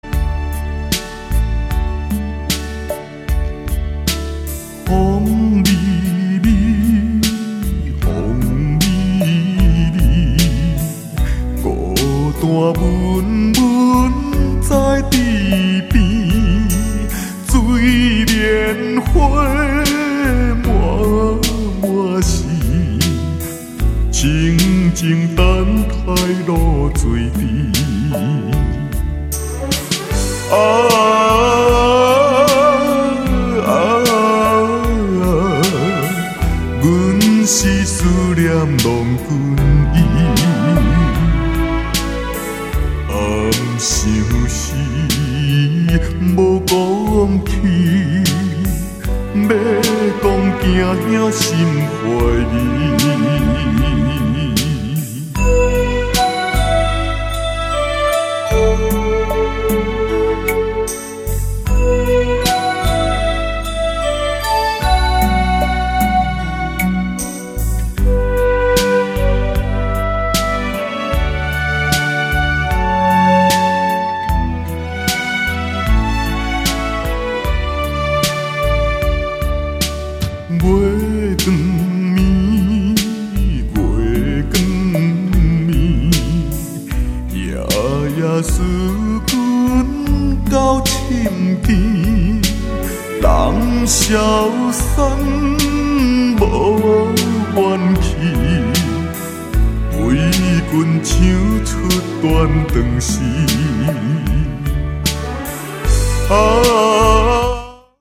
優美的弦樂伴奏
洋溢著濃濃的感情
音質清透飽滿
由他渾厚有力的嗓音詮釋多首台灣歌謠，不論是失去愛情、希望破碎、生活艱困還是思念故鄉，都十足扣人心弦。